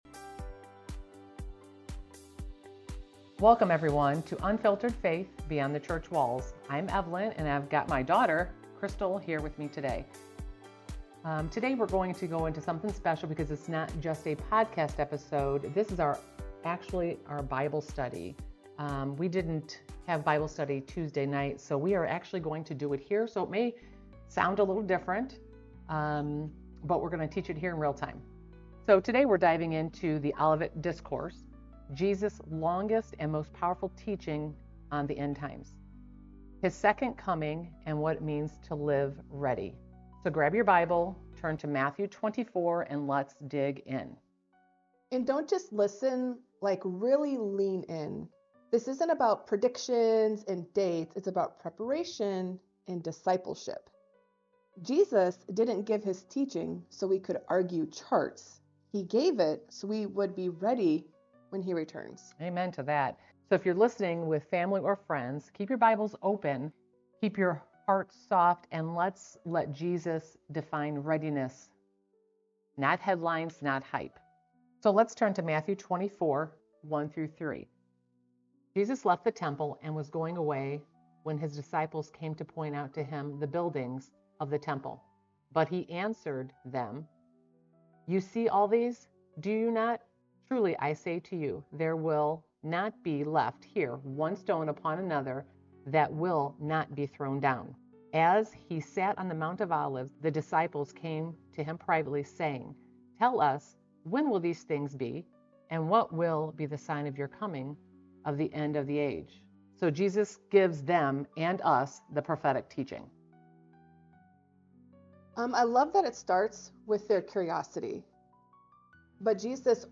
In this live Bible study + podcast, we unpack Jesus’ Olivet Discourse (Matthew 24–25): the signs of the times, the Abomination of Desolation, the Great Tribulation, the visible return of Christ, and the Final Judgment. Then we get to Jesus’ real emphasis—readiness. Through the Fig Tree, Ten Virgins, Talents, and the Faithful Servant, we explore how to guard against deception, endure in love, and stay filled with the Spirit while serving “the least of these.”